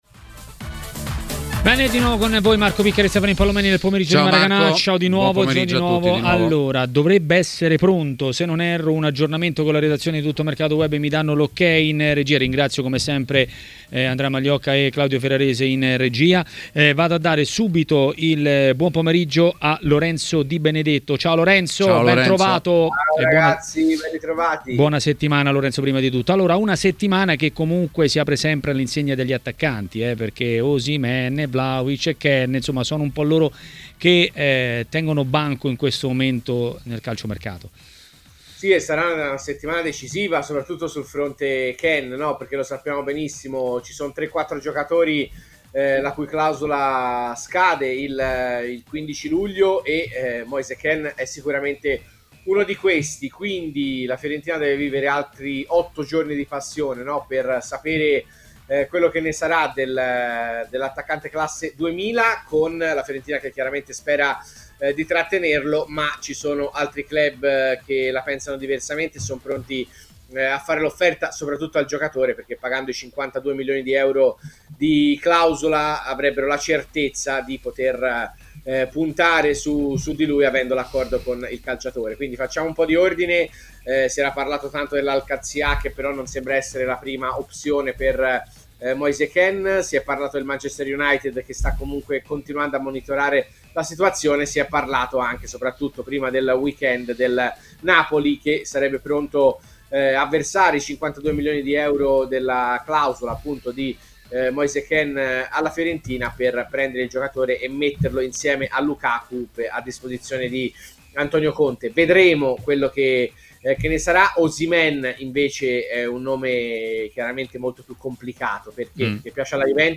Massimo Caputi è intervenuto a Maracanà, nel pomeriggio di TMW Radio.